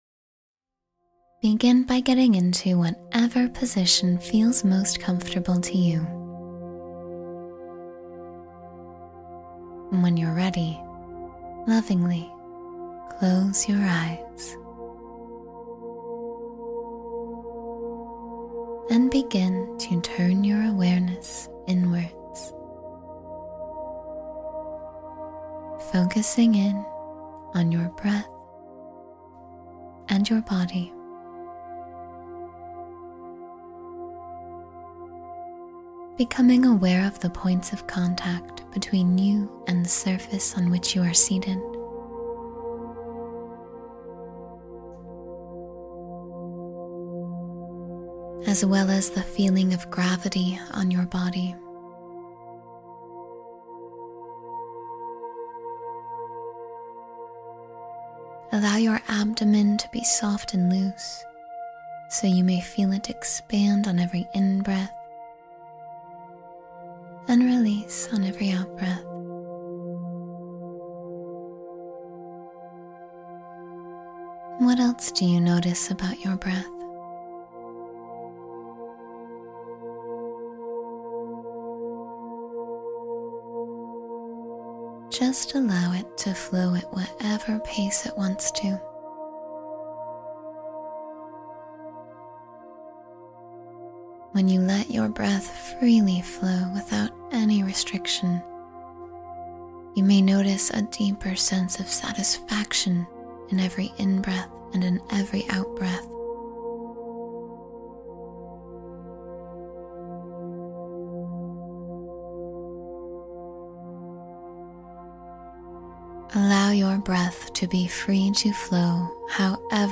Sleep Peacefully 10 Minutes Before Bed — Guided Meditation for Relaxation